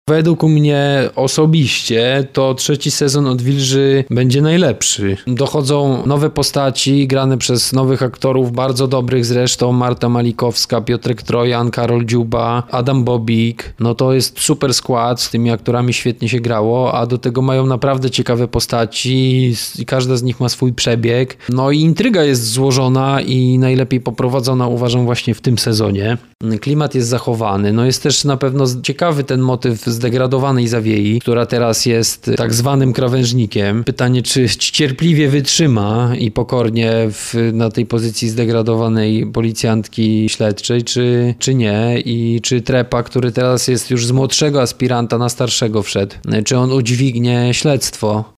w rozmowie z Twoim Radiem